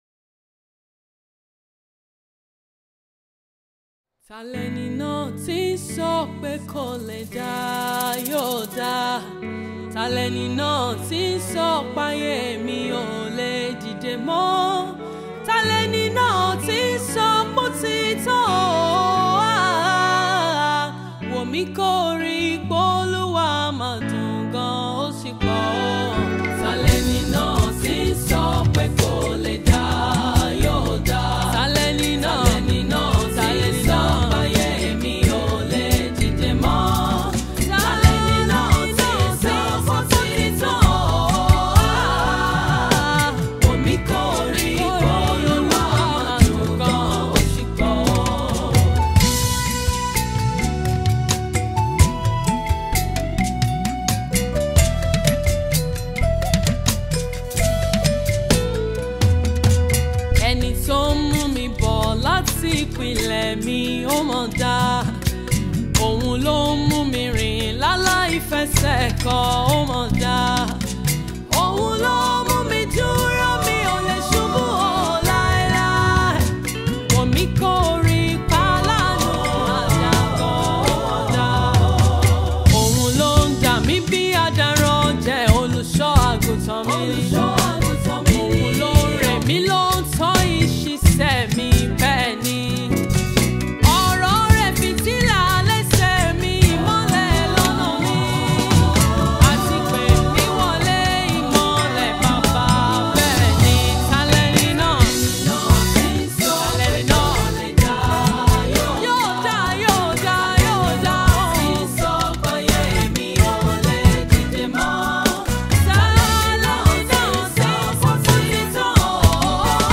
Indigenous Nigerian christian singer-songwriter